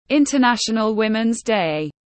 Ngày quốc tế phụ nữ tiếng anh gọi là International Women’s Day, phiên âm tiếng anh đọc là /ˌɪn.təˈnæʃ.ən.əl ˈwɪm.ɪn deɪ/
International Women’s Day /ˌɪn.təˈnæʃ.ən.əl ˈwɪm.ɪn deɪ/